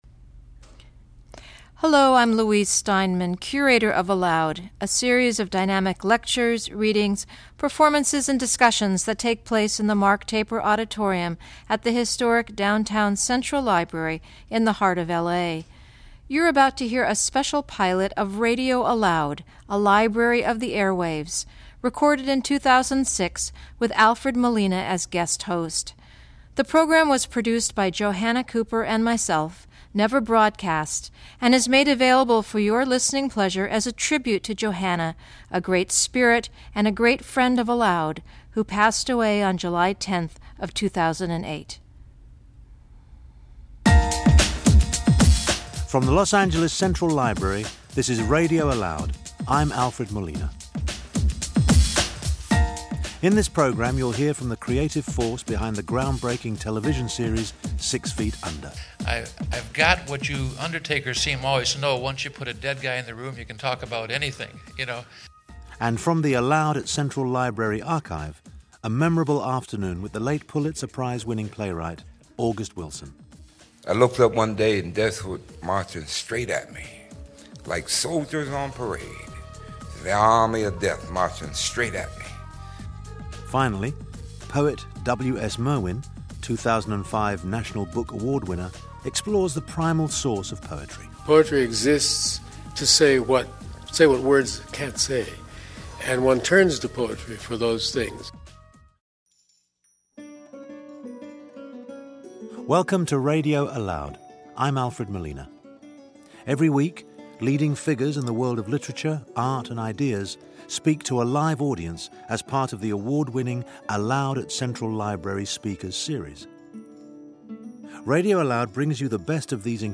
This pilot radio program (never broadcast) is comprised of excerpts from three ALOUD programs: a December 13, 2005 conversation between \"Six Feet Under\" writer/producer Alan Ball and writer/funeral director Thomas Lynch; a public talk on April 2, 2003 by playwright August Wilson, recipient of the 2003 Los Angeles Public Library Literary Award; and an April 4, 2005 poetry reading by W.S. Merwin. Guest Host: Alfred Molina.